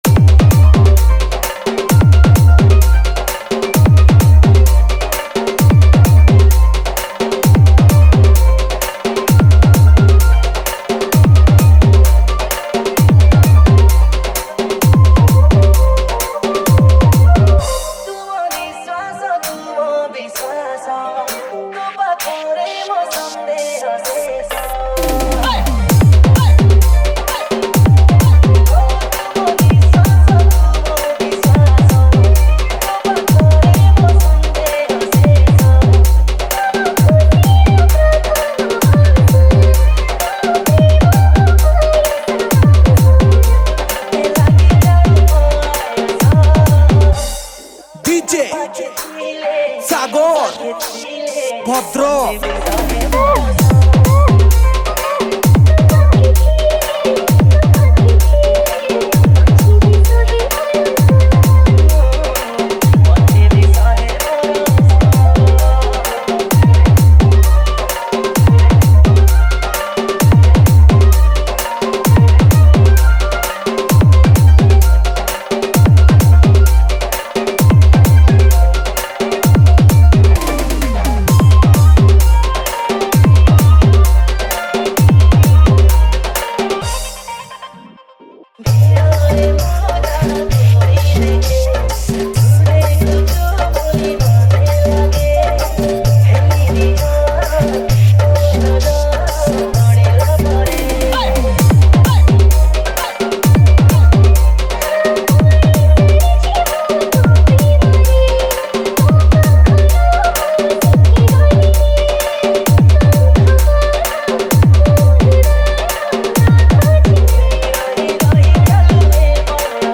Category:  New Odia Dj Song 2024
Odia Romantic Dj Song